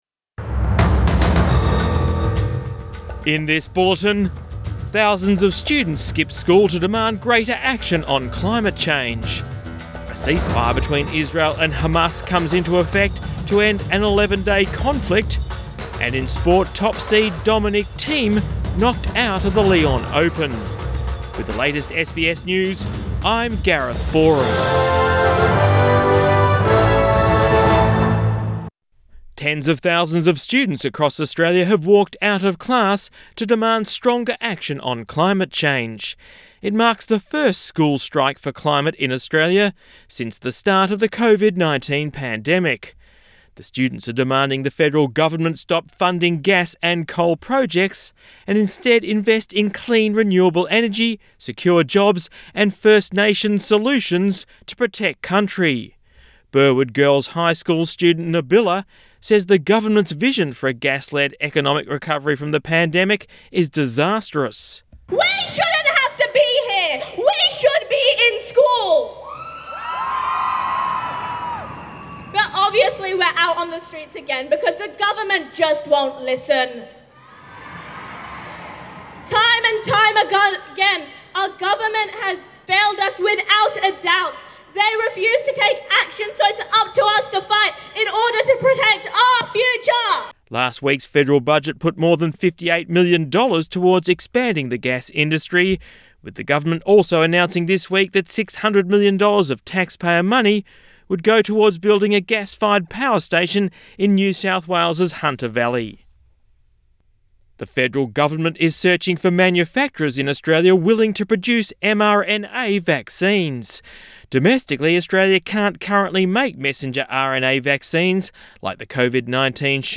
PM bulletin 21 May 2021